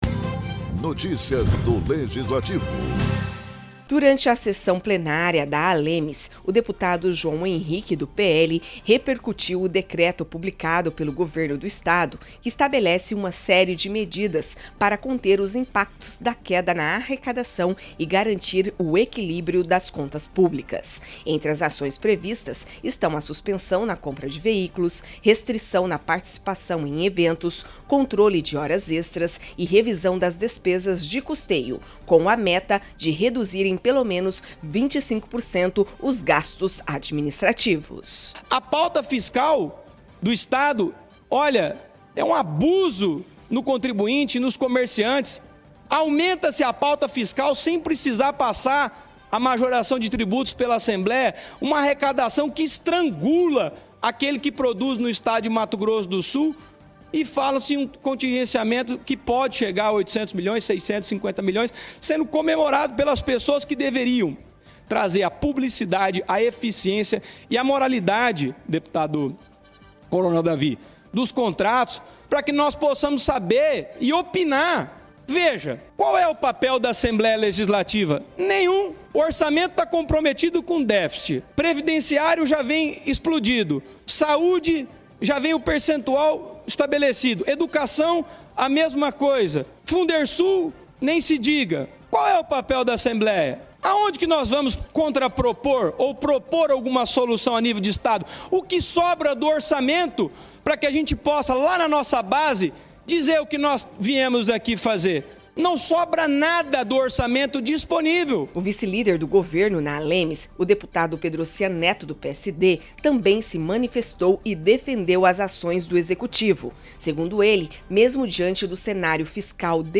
Durante a sessão plenária da Assembleia Legislativa, deputados repercutiram o decreto publicado pelo Governo de Mato Grosso do Sul que estabelece medidas de contenção de despesas. A iniciativa busca enfrentar a queda na arrecadação e garantir o equilíbrio fiscal, com previsão de reduzir em 25% os gastos administrativos.